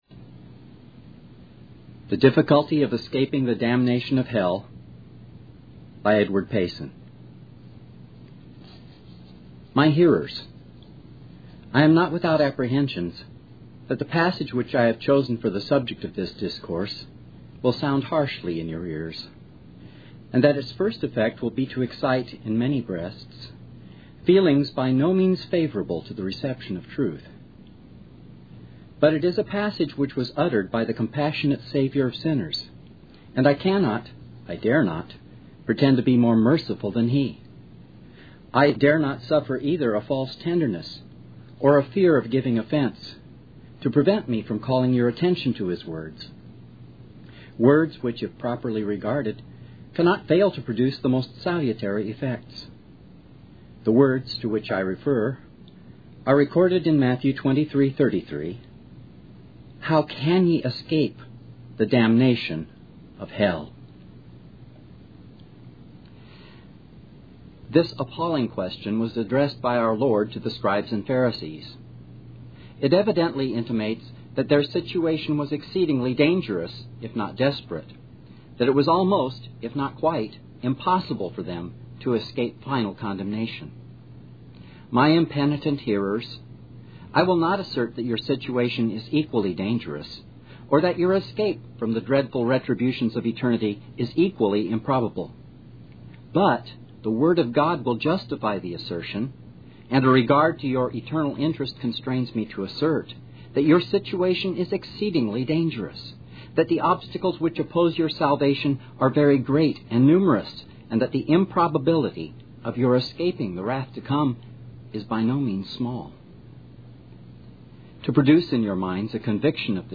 The Difficulty of Escaping the Damnation of Hell (Reading) by Edward Payson | SermonIndex